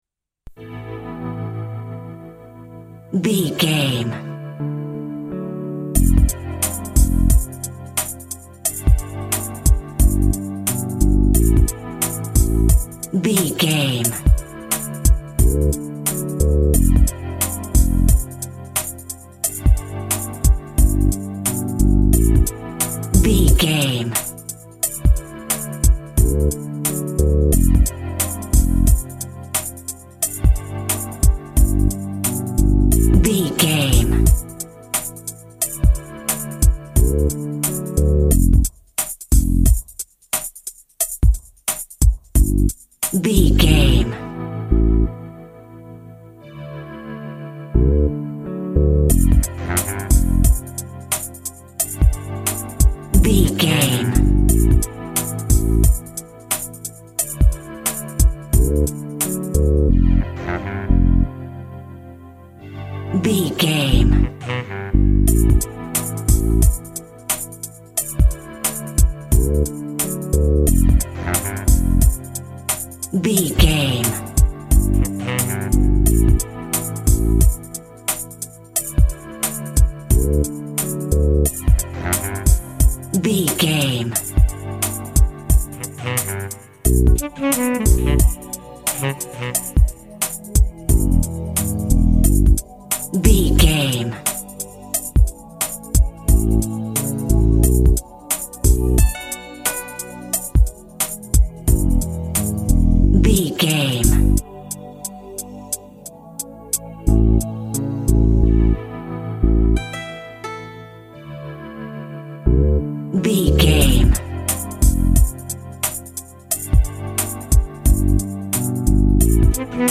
Aeolian/Minor
hip hop
synth lead
synth bass
hip hop synths
electronics